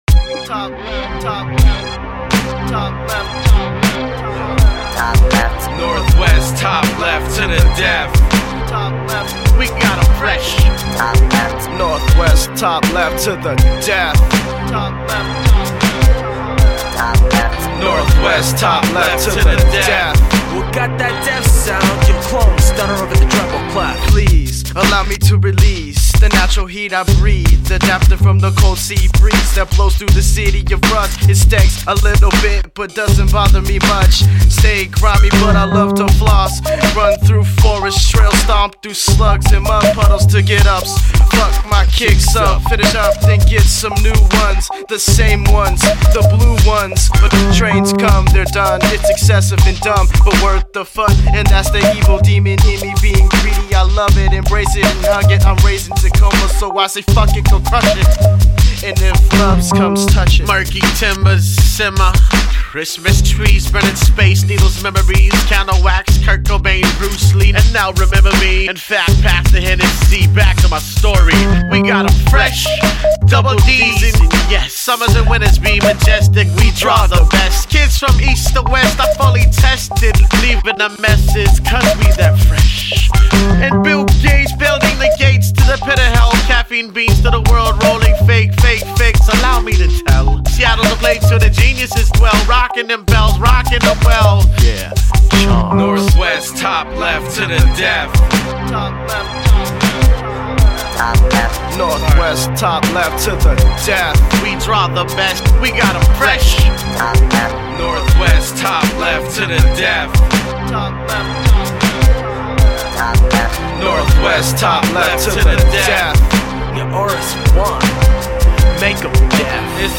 hip hop collective